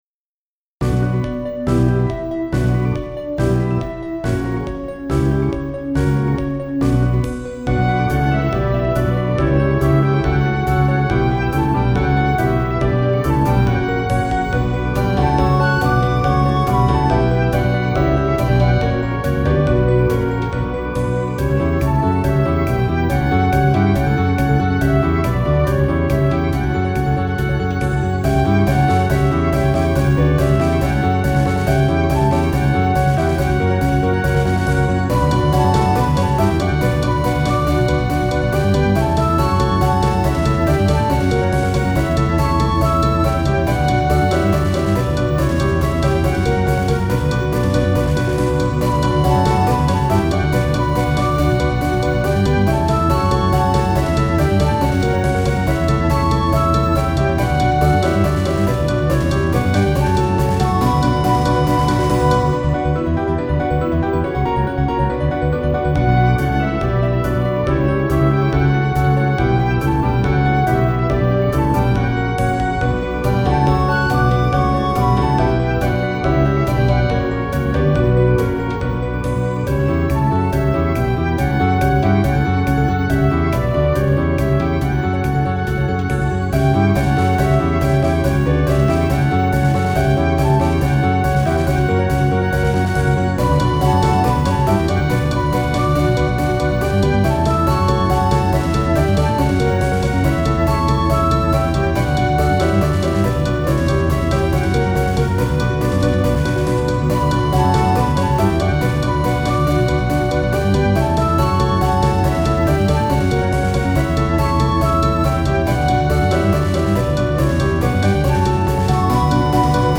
ロ短調